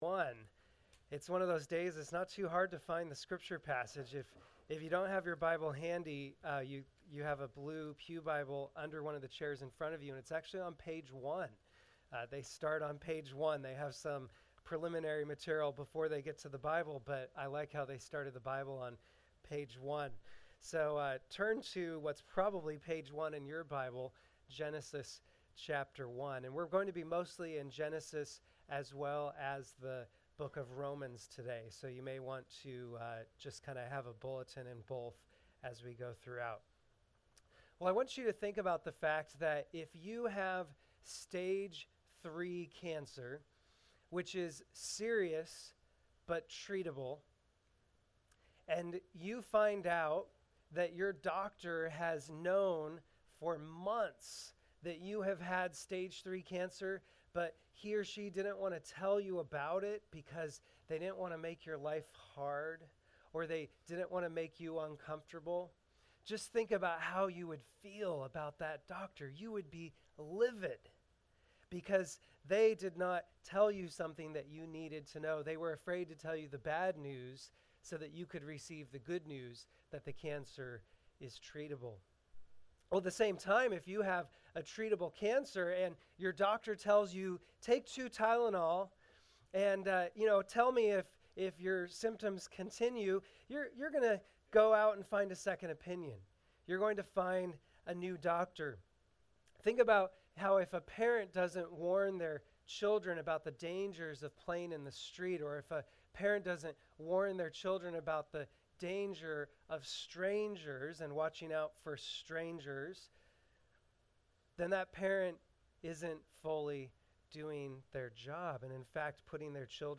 People (#2 of 5 in the “Good News” Sermon Series)